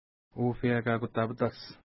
Pronunciation: ufeja ka:kuta:kuta:pita:s
Pronunciation